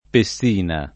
Pessina [ pe SS& na ]